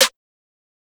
TS Snare_8.wav